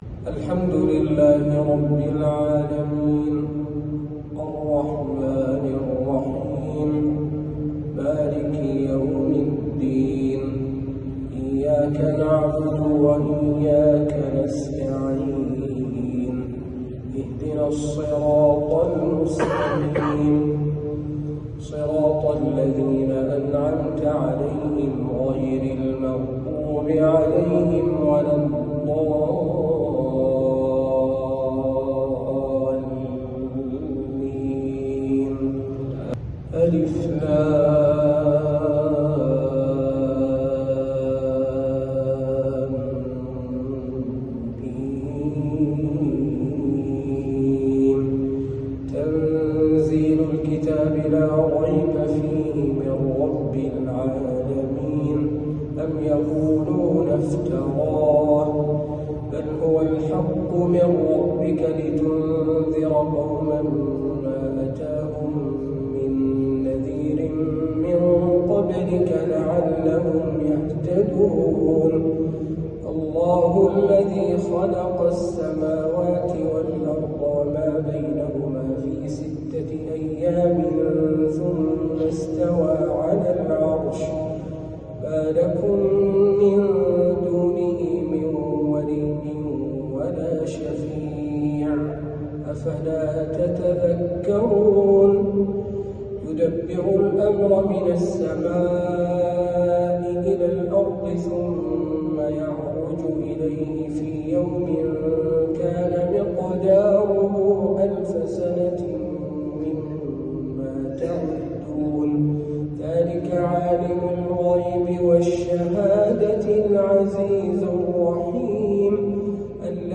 تلاوة لسورتي السجدة والإنسان